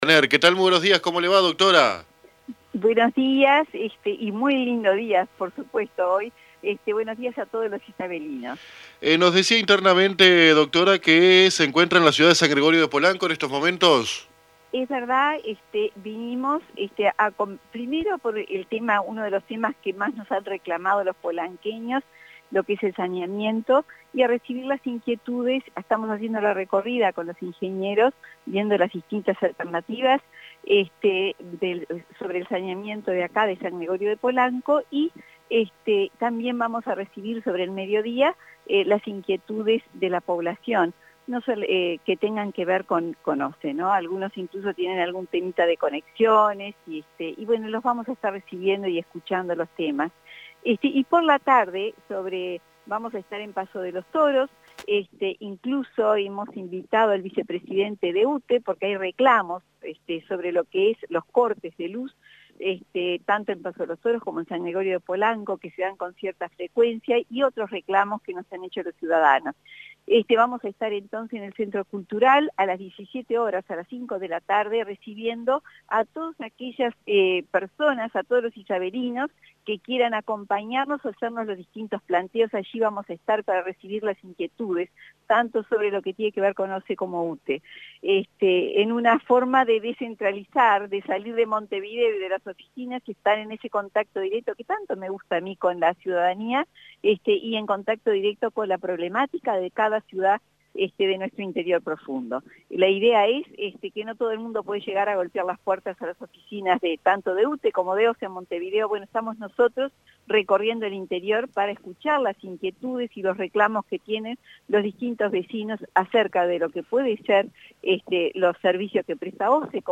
Montaner se expresó a AM 1110 acerca de su visita a San Gregorio de Polanco en la mañana, y a Paso de los Toros en la tarde, con Sanguinetti Canessa, a quien invitó.